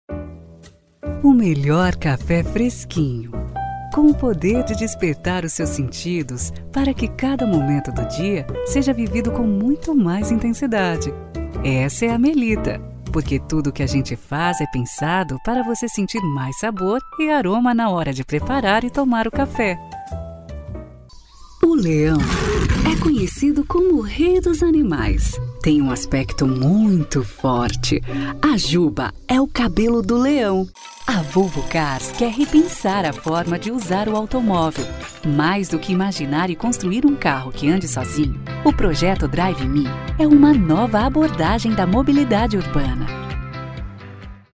16 years of experience, my interpretation is smooth and pleasant, with a captivating melodic style, bringing credibility and adding value to your brand.
Sprechprobe: Werbung (Muttersprache):
My interpretation is smooth and pleasant, with a catchy melodic style, bringing credibility and adding value to the brands.